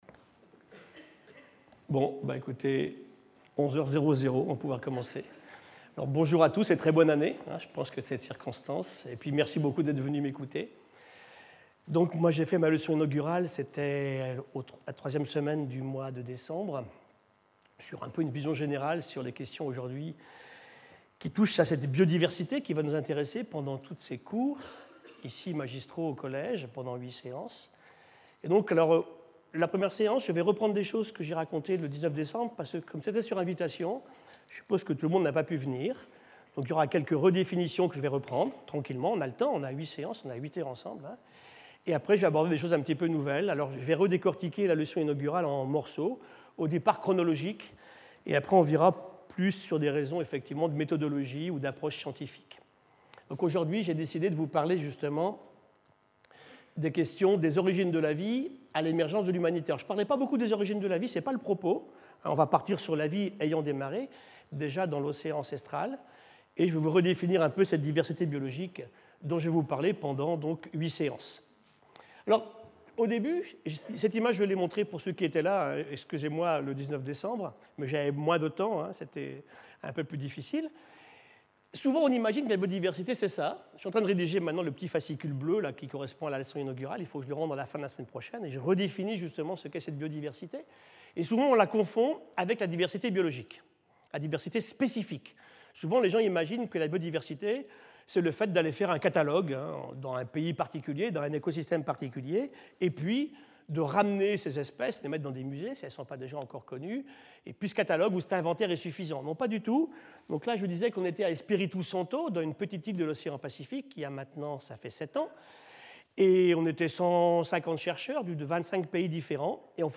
Gilles Boeuf Professeur invité, Collège de France